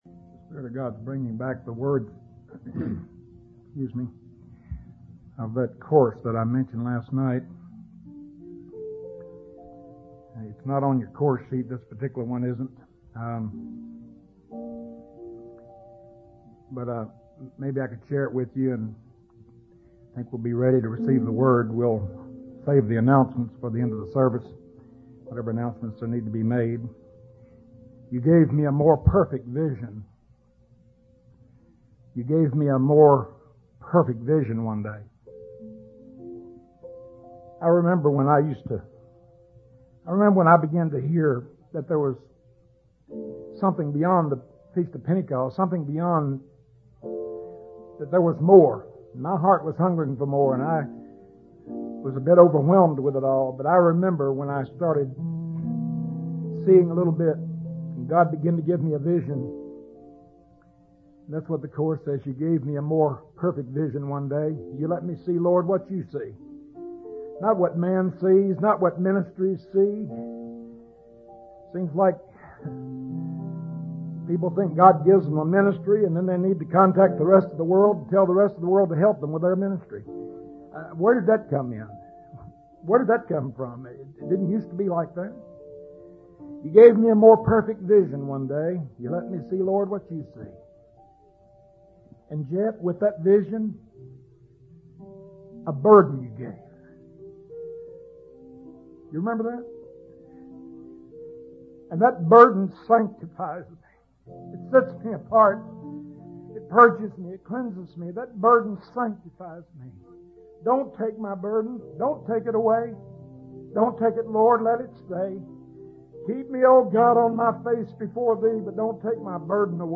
In this sermon, the preacher emphasizes the importance of the harvest and the end result of God's work. He compares the time of soaring, which is good, to the harvest, which is even better. The preacher highlights that God is the gardener and He is waiting for the precious fruit of the earth.